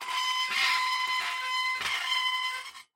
Звуки калитки
Скрип двери при отодвигании от себя